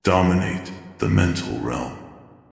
This voice set comes with reverberation echo effect, and the voice content is related to the attack type mind control.